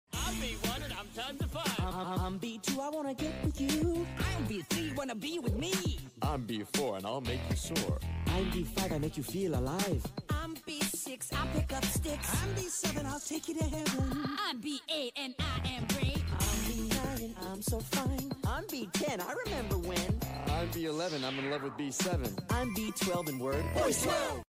☆Ferret ☆European pine marten ☆Fisher ☆Wolverine ☆Giant river otter ☆European badger ☆Long-tailed weasel ☆Greater grison ☆Saharan striped polecat (zorilla) ☆American mink ☆Sea otter ☆Burmese ferret-badger